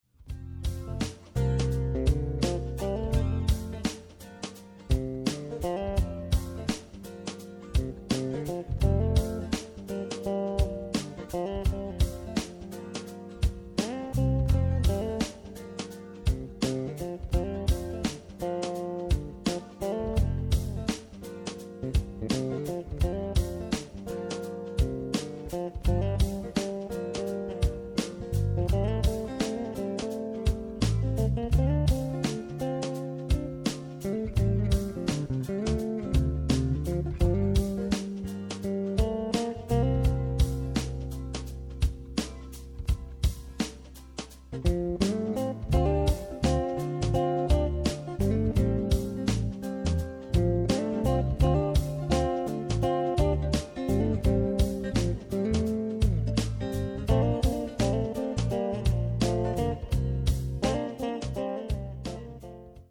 Fender "Piccolo Bass"
Jazz, Gospel und Rhythm & Blues sind vertreten.